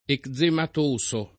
eczematoso [ ek z emat 1S o ]